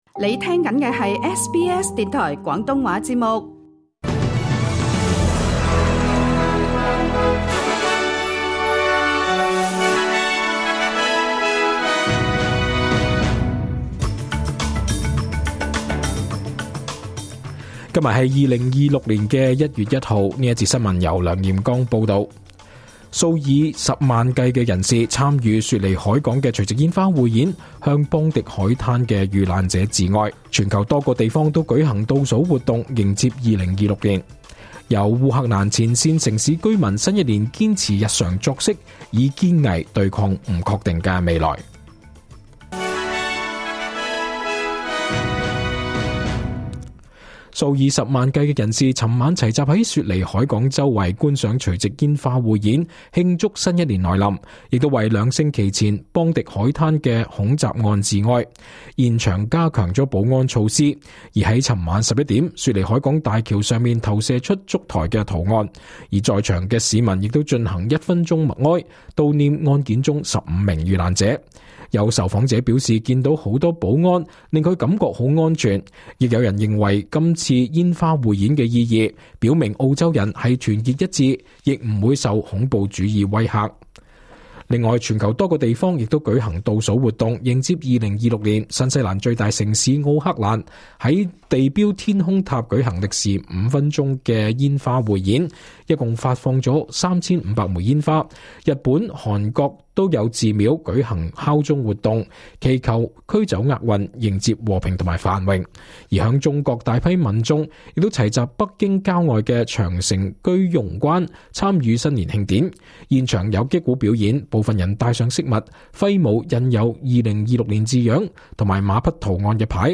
2026 年 1 月 1 日 SBS 廣東話節目詳盡早晨新聞報道。